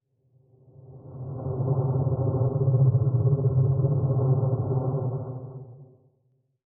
Fantasy Creatures Demo
Bug_rare_7.wav